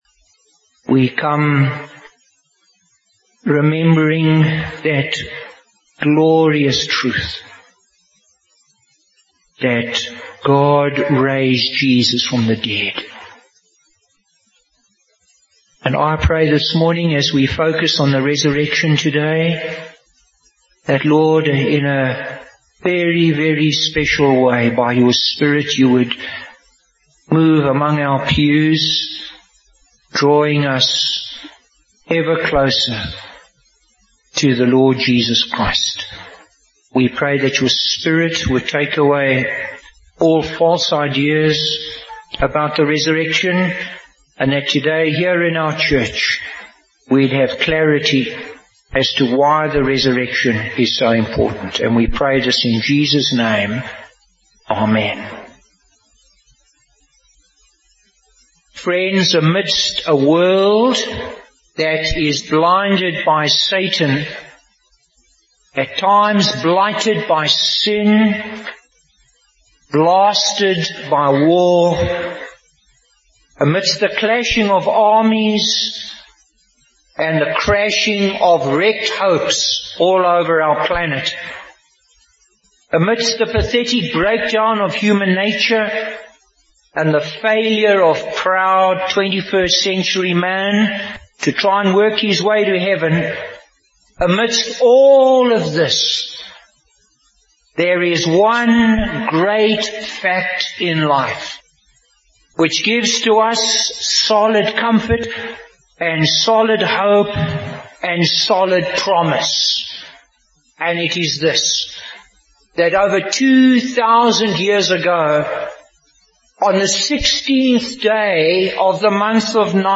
Bible Text: Psalm 121:1, Mark 16:1-8 | Preacher: Bishop Warwick Cole-Edwards